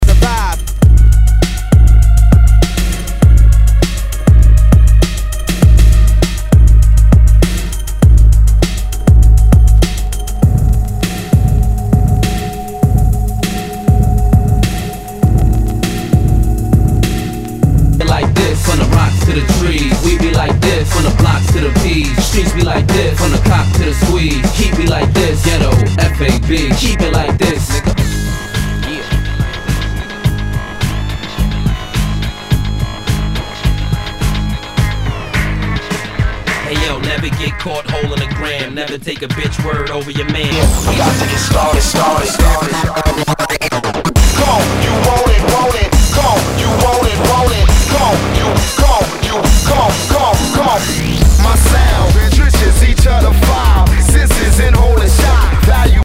HIPHOP/R&B
ナイス！ヒップホップ / ブレイクビーツ！
盤に傷あり全体にチリノイズが入ります。
[VG-] 傷や擦れが目立ち、大きめなノイズが出る箇所有り。